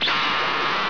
File: "Laser #3"
Type: Sound Effect